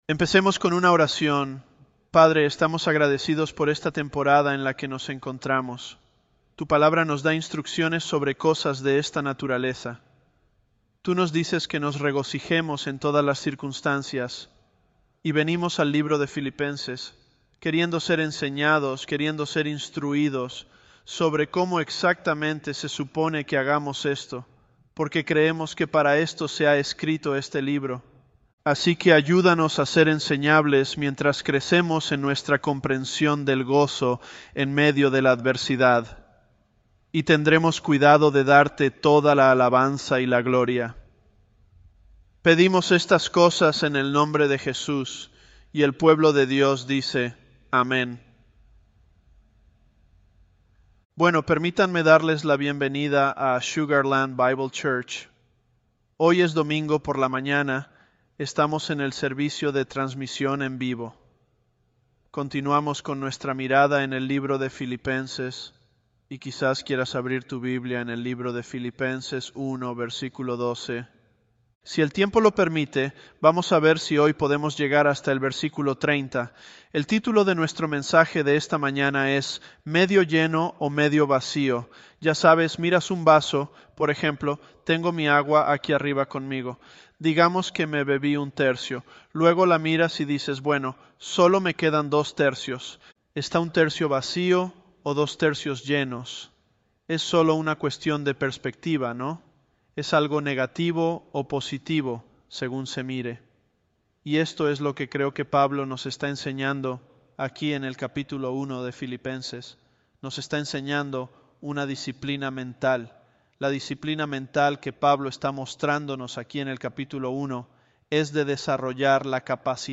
Philippians 003 – Medio Lleno o Medio Vacio? Home / Sermons / Philippians 003 - Medio Lleno o Medio Vacio?
Elevenlabs_Philippians003.mp3